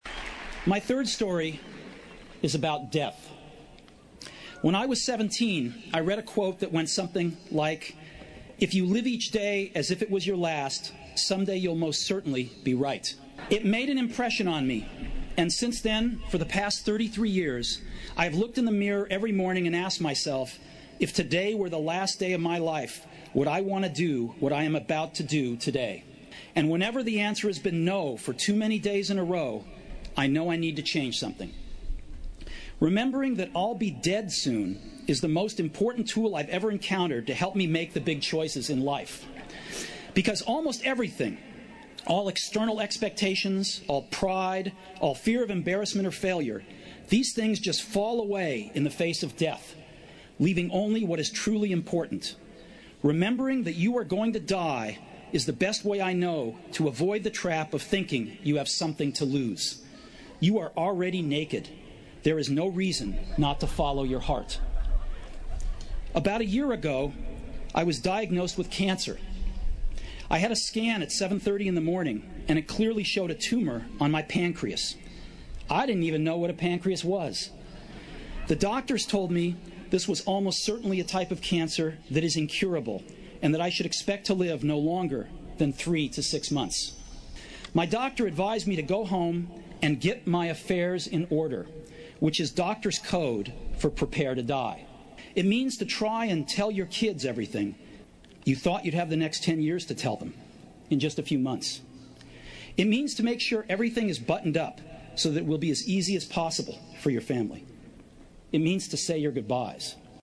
名校励志英语演讲 44:我人生中的三个故事 听力文件下载—在线英语听力室